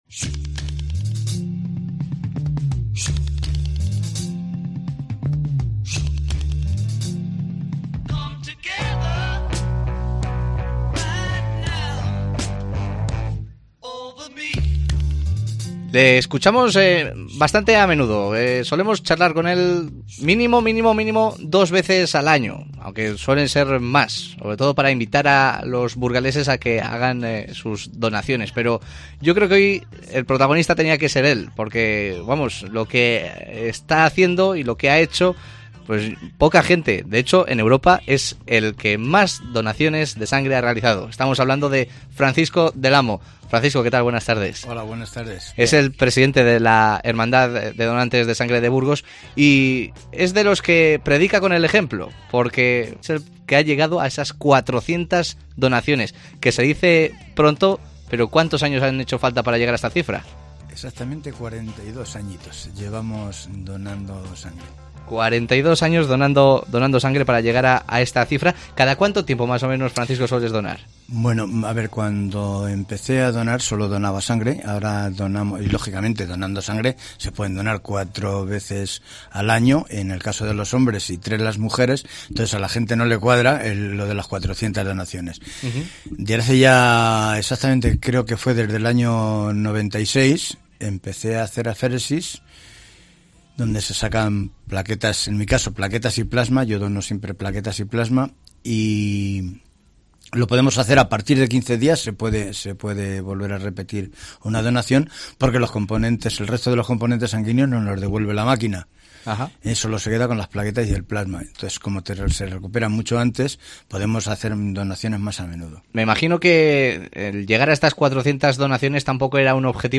AUDIO: Ha pasado por los micrófonos de COPE Burgos para contarnos su historia, llena de compromiso y solidaridad.